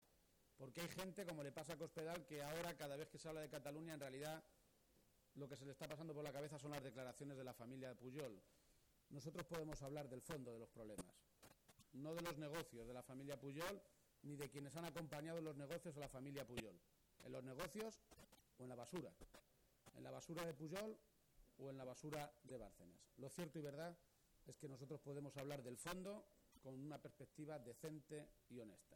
El secretario general del PSOE de Castilla-La Mancha, Emiliano García-Page, ha visitado hoy la Feria de Talavera y allí, a 24 horas de hacer oficial su candidatura a las primarias para ser quien compita por la Presidencia de Castilla-La Mancha, ha hecho un contundente anuncio:”Si en Mayo soy el Presidente de Castilla-La Mancha y antes de Agosto no hay encima de la mesa un Plan de Empleo para 60.000 parados, yo dimitiré”.